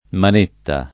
[ man % tta ]